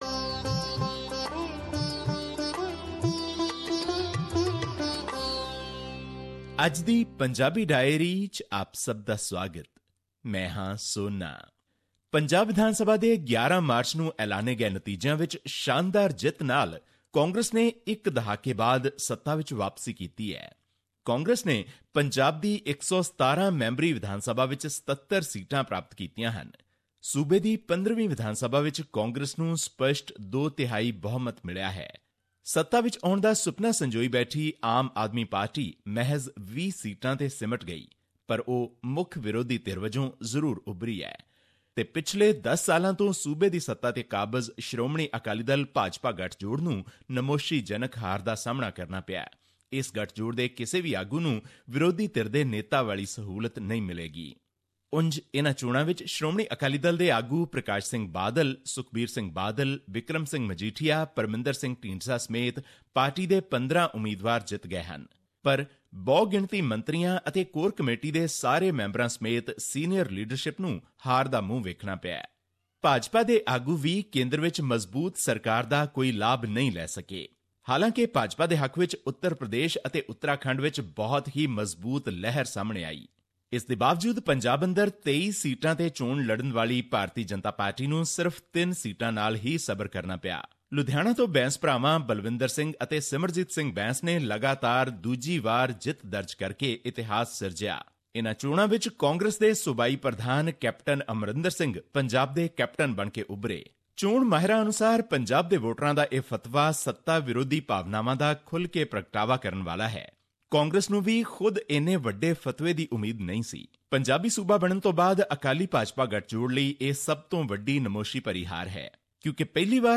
You will also hear from Captain Amarinder Singh, Parkash Singh Badal, HS Phoolka, Navjot Sidhu, Sukhbir Badal, Sukhpal Singh Khaira, and many more candidates who contested the Punjab 2017 Vidhan Sabha elections.